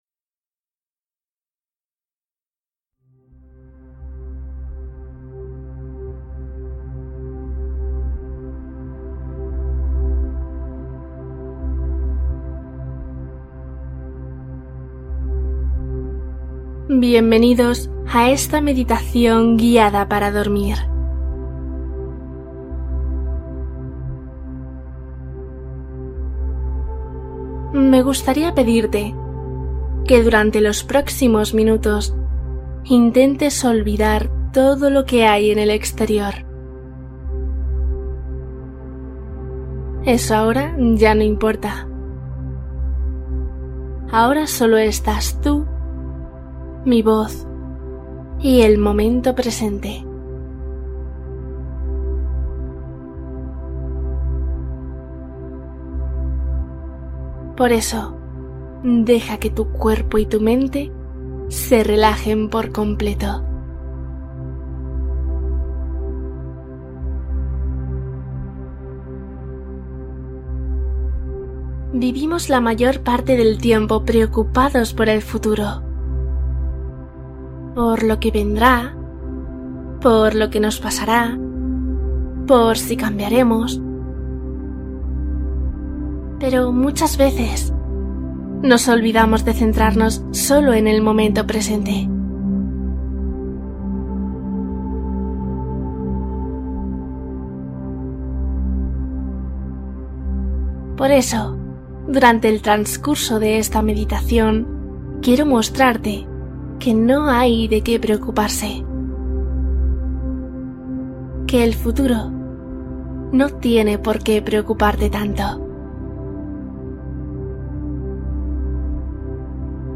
Viaje al futuro: cuento y meditación para dormir profundamente